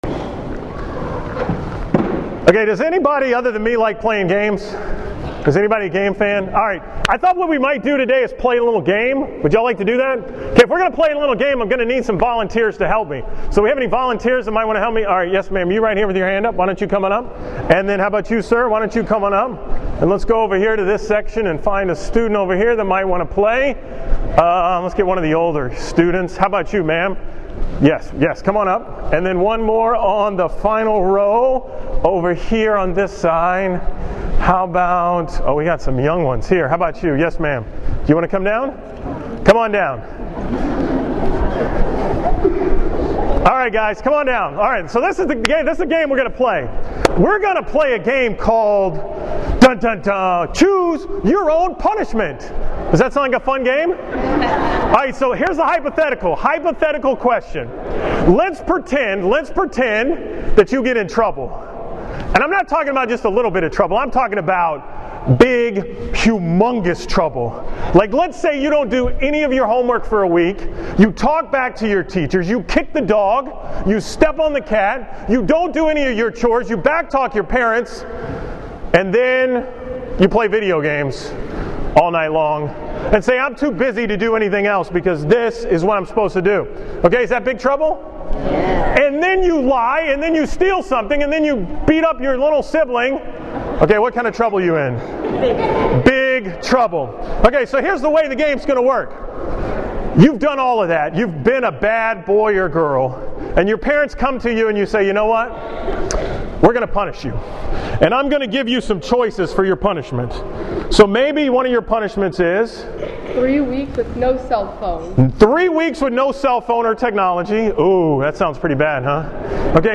From the school Mass at St. Theresa's in Houston on February 3rd